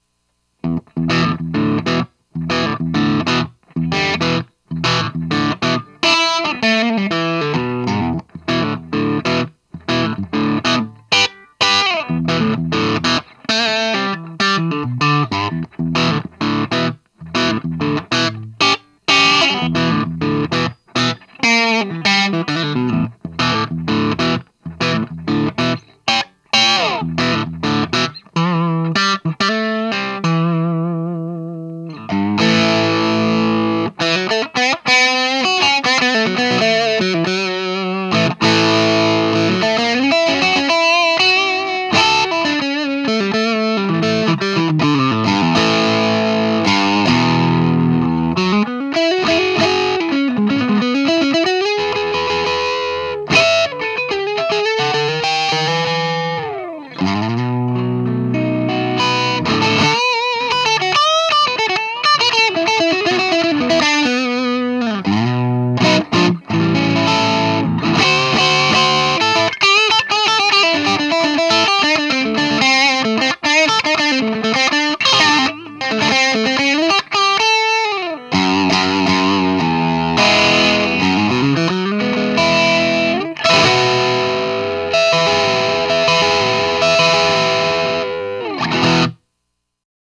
■No.7 　TUBE CRUNCH
G : GIBSON 1996 ES335 WITH 1957 CLASSIC HUMBUCKERS - MIDDLE POSITION
Amp：ENGL POWERBALL HEAD THRU ENGL 4X12 CAB LOADED
WITH VINTAGE 30 WATT CELESTIONS
Mode SW：CLASSIC / Voltage SW：18V
LEVLEL:12 / DRIVE:11 / MID:3 / TONE:1 US version
ST9Pro+_No.7_18-1_CLASSIC.wav